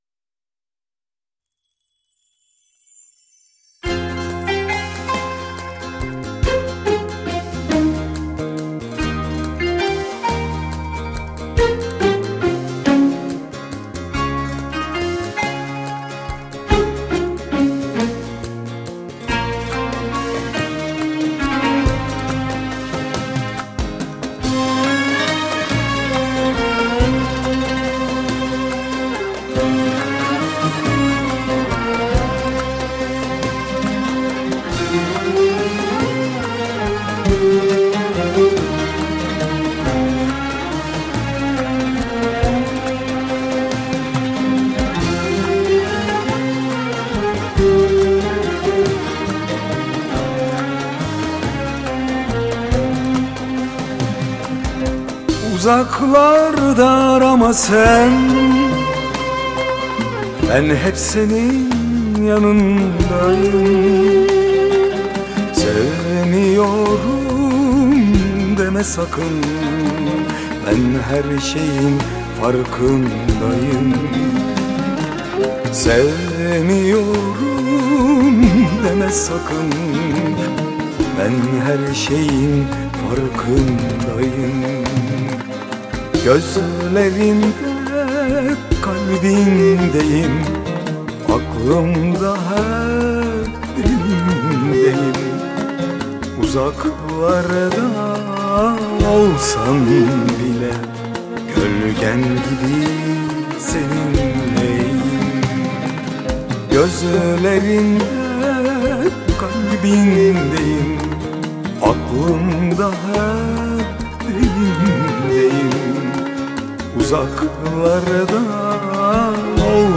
Eser Şekli : Pop Fantazi